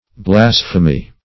Blasphemy \Blas"phe*my\, n. [L. blasphemia, Gr.